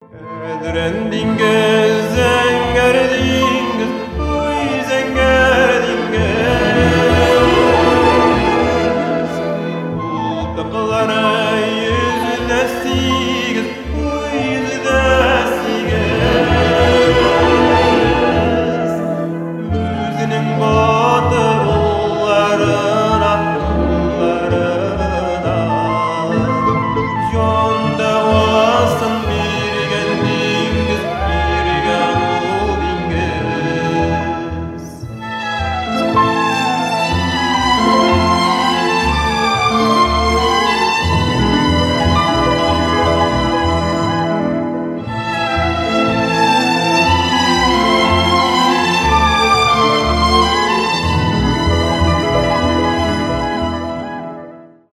романтические , татарские
инструментальные , поп